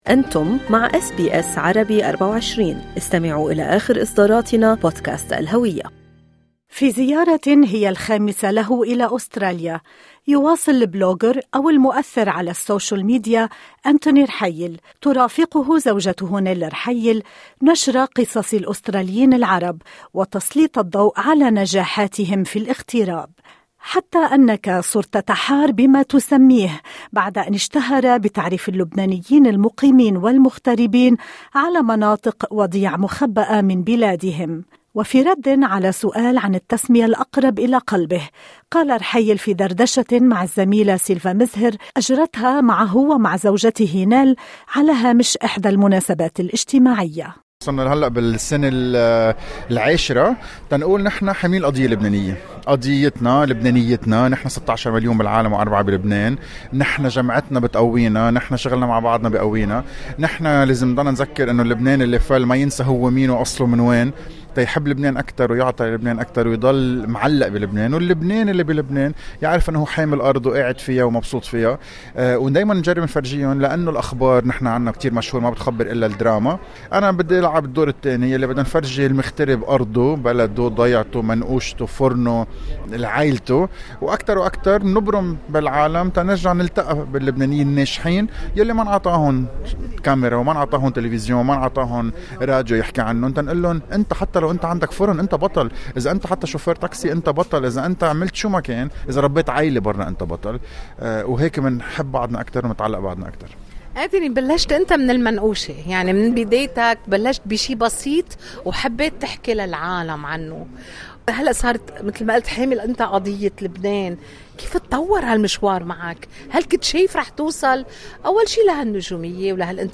دردشة مع أس بي أس عربي24